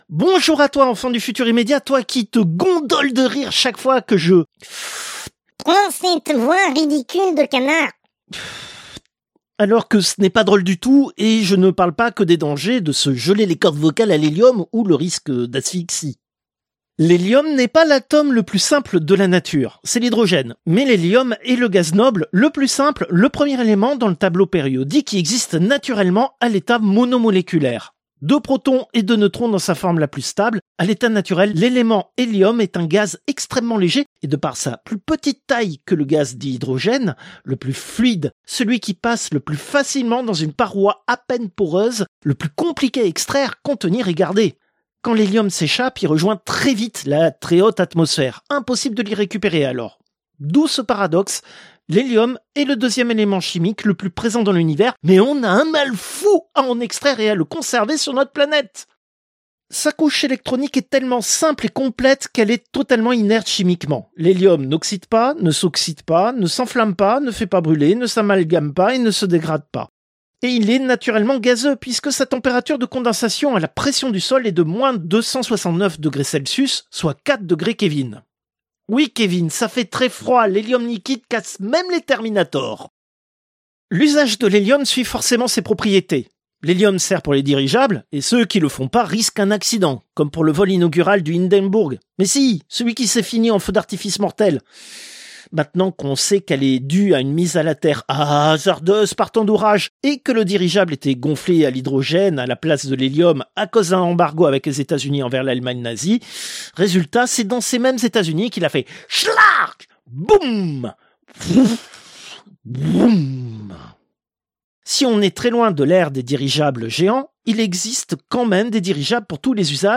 Extrait de l'émission CPU release Ex0241 : lost + found (avril 2026).
[Pour des raisons évidentes de cohérence avec le contenu de la chronique et de sécurité du personnel humain, aucun gramme d'hélium n'a été utilisé pour produire la voix de canard, grâce à l'usage d'un habile trucage technique]